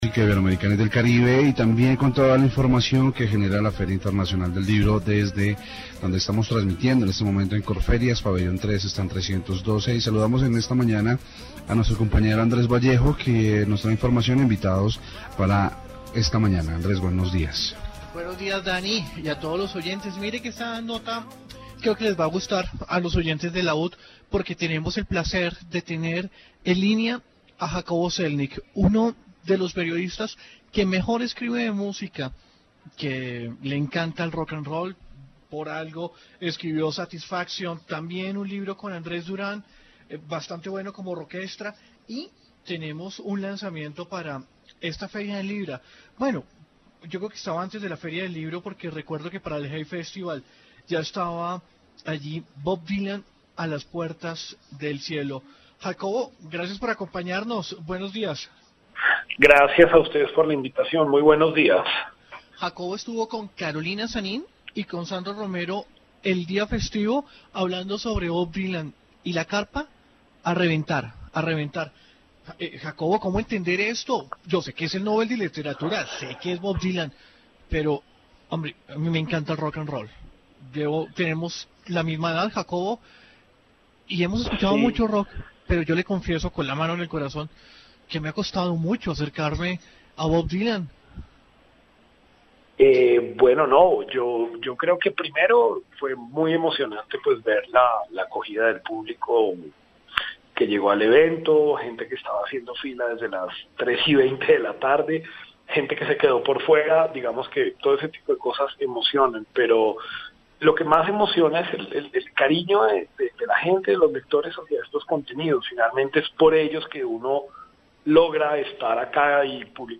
Feria del Libro 2017. Informe radial